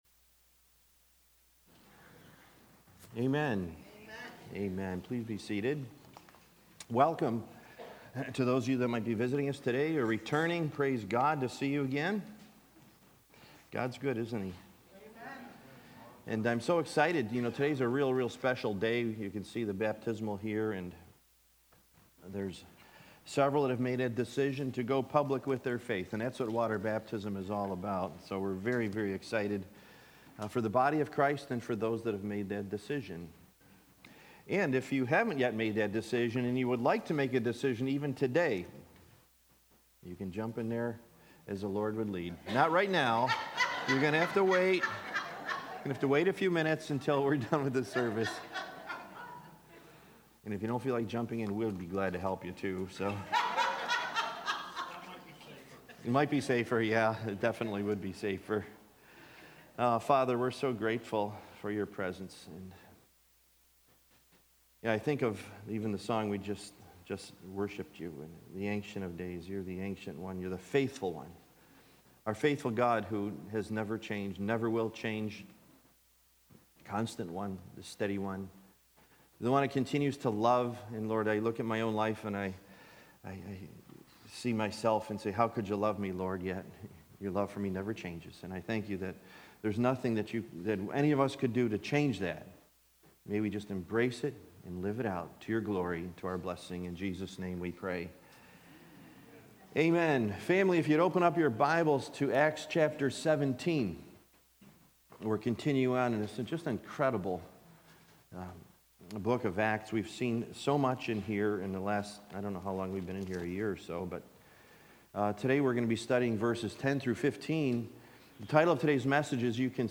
Acts 17:10-15 “Readiness In Berea” Followed By Water Baptism
Series: Sunday Morning